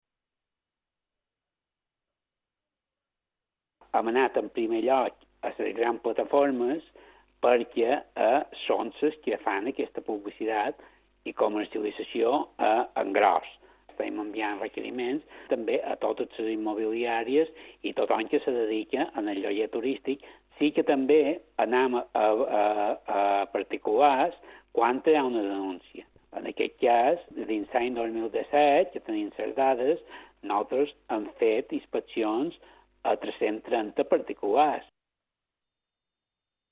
En aquest sentit, Turisme va realitzar 330 inspeccions a particulars durant l’any passat . Antoni Sansó és director general de Turisme en declaracions a IB3 Ràdio.